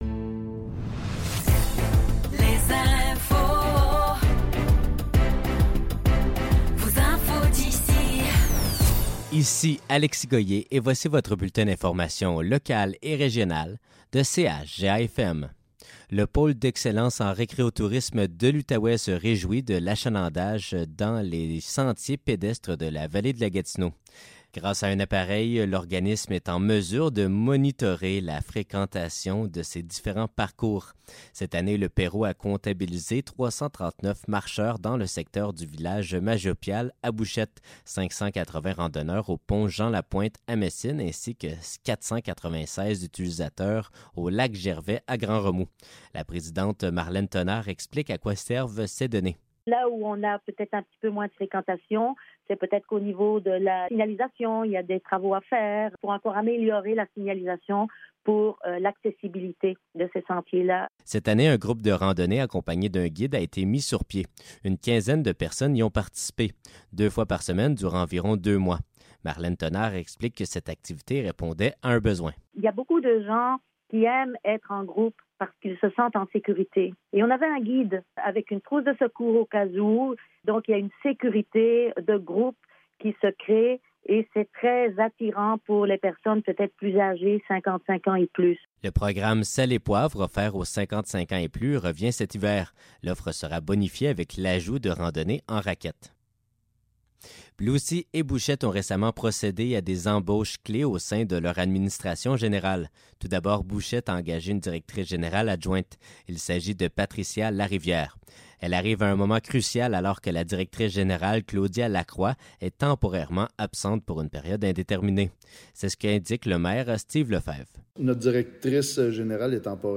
Nouvelles locales - 14 novembre 2024 - 12 h